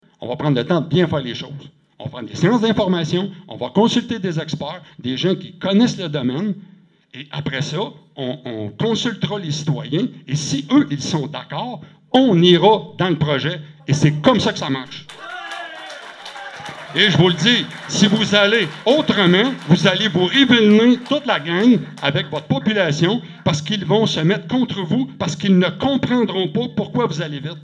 Fait rare, la séance du conseil des maires s’est tenue dans une salle remplie presque qu’au maximum de sa capacité, à Grand Saint-Esprit.
Au micro, plusieurs ont déploré l’empressement des élus à organiser des séances d’information avec l’intention d’appuyer, dès le début juin, le dépôt de projets à Hydro-Québec.